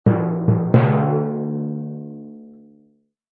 Descarga de Sonidos mp3 Gratis: timpani 2.